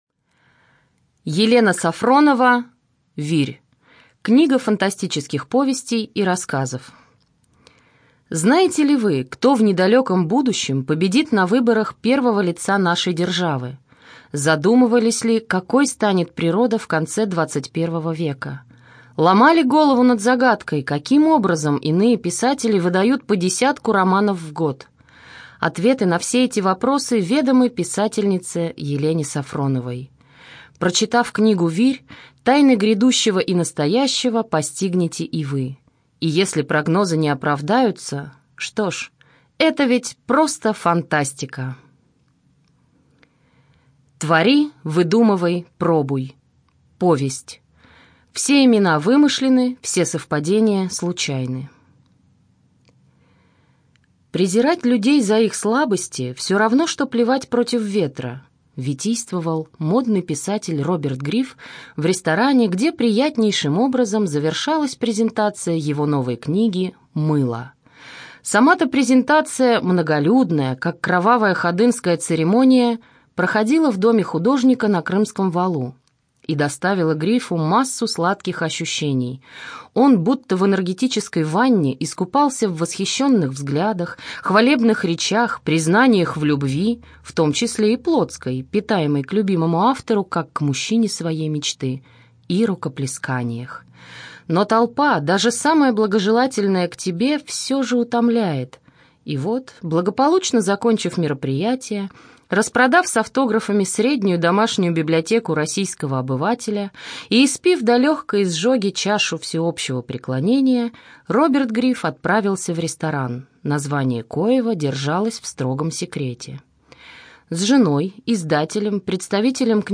Студия звукозаписиРязанская областная специальная библиотека для слепых